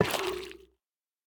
Minecraft Version Minecraft Version snapshot Latest Release | Latest Snapshot snapshot / assets / minecraft / sounds / block / sculk_shrieker / place2.ogg Compare With Compare With Latest Release | Latest Snapshot